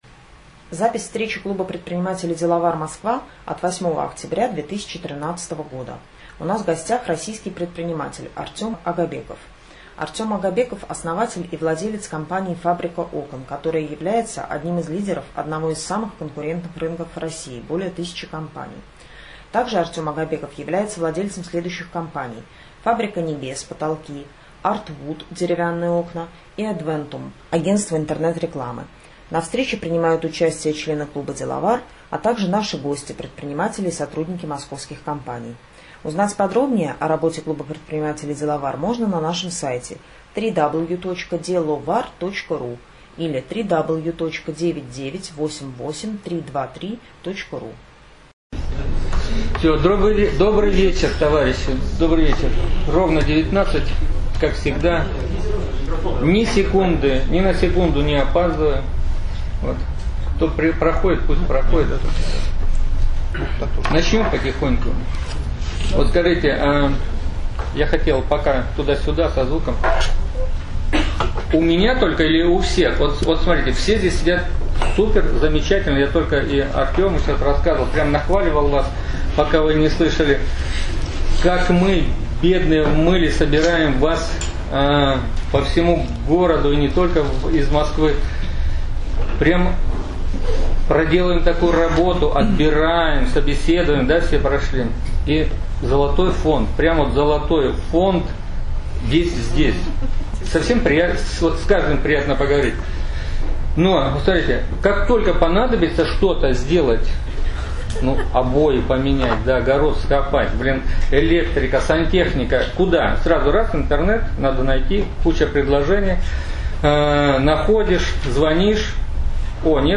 8 октября 2013 года с 19:00 до 22:00 состоялась очередная встреча клуба предпринимателей Деловар.